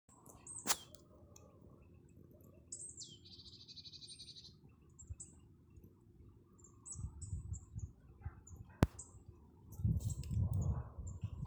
Птицы -> Синицевые ->
гаичка, Poecile palustris